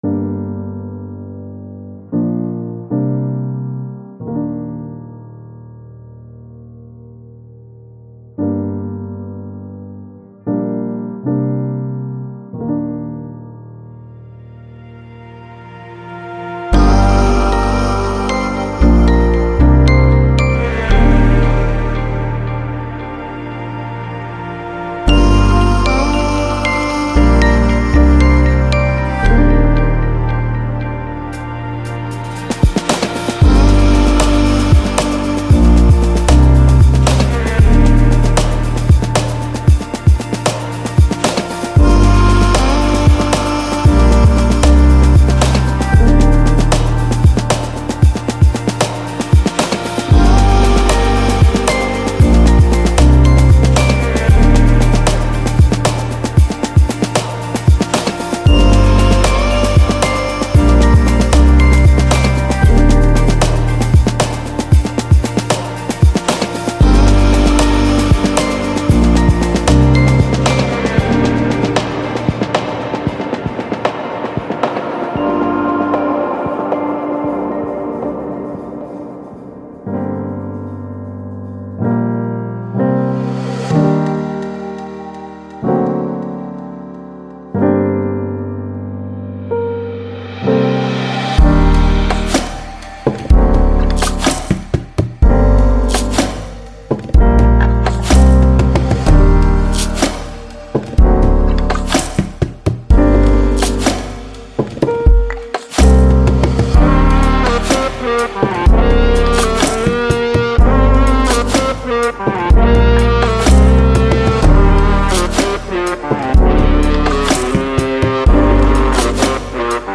• 67温暖的鼓声
• 10个厚重的低音圈（包括Midi文件）
• 27个大气旋律循环（包括Midi文件）
• 节奏-115，125BPM